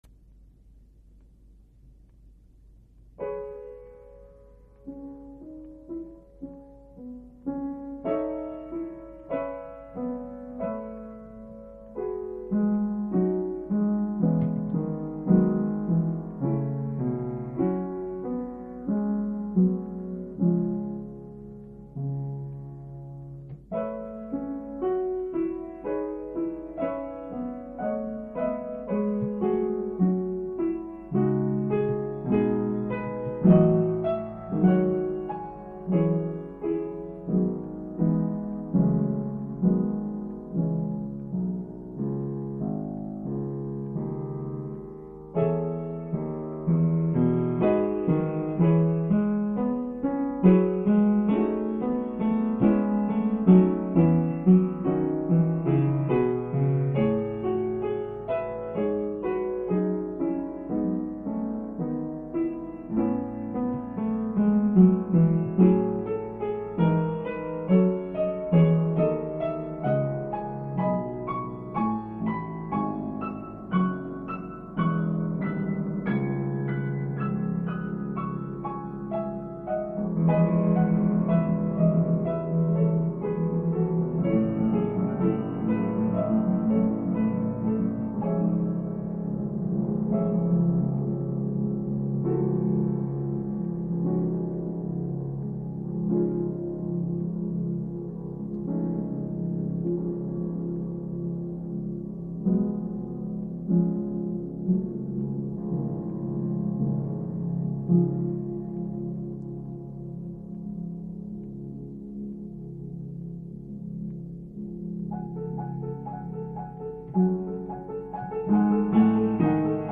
Eine Klavierimprovisation aus dem Dezember 1990, noch im "Château" entstanden, in der einige  Reminiszenzen an die Gregorianik und an Maurice Duryflé durchschimmern.